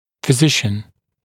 [fɪ’zɪʃ(ə)n][фи’зиш(э)н]терапевт, врач, доктор, медик